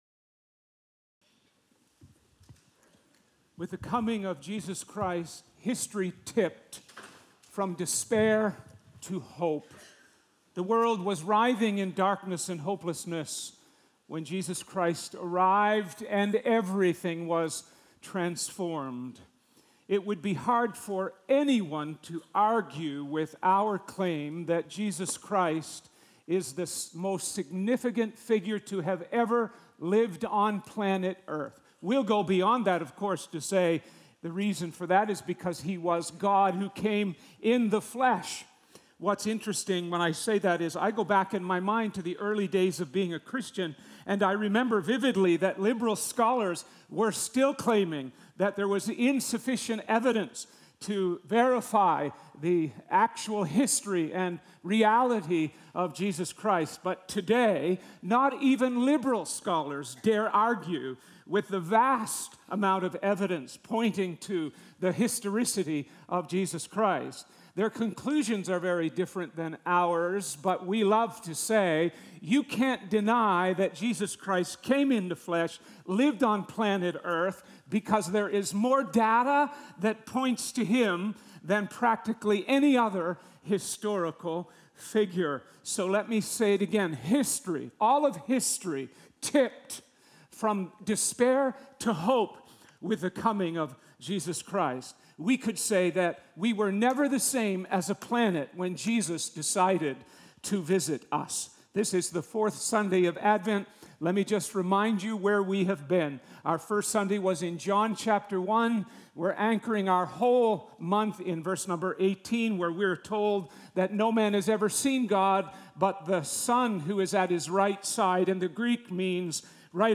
4th Sunday of Advent | A Revelation of HOPEScripture: Luke 2:1–20